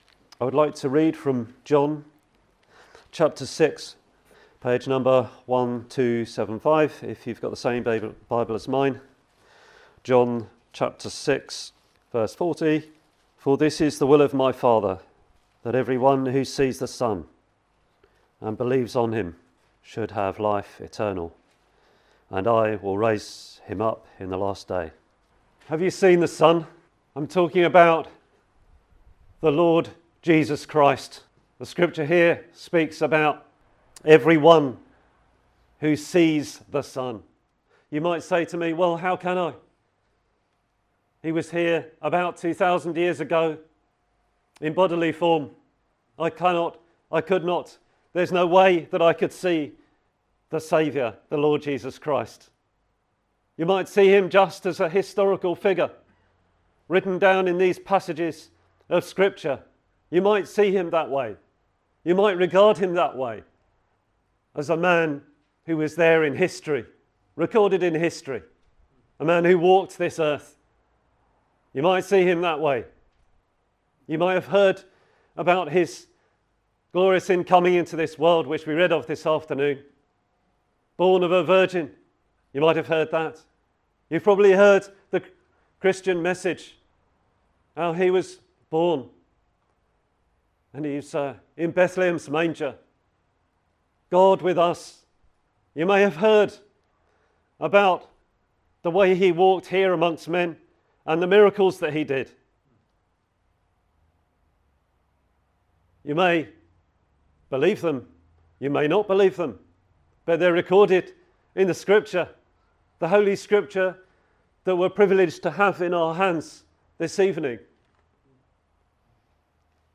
This preaching invites you to consider the most important question: have you seen the Son (Jesus Christ) for yourself?